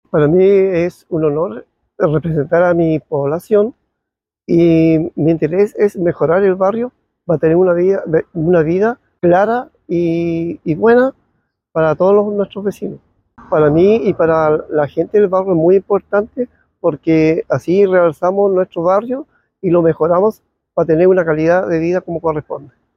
Con gran éxito se desarrolló el Primer Encuentro de Seguridad Barrial, como parte del Plan de Confianza Social del Programa Quiero Mi Barrio, en la población García Hurtado de Mendoza, en Rahue Alto.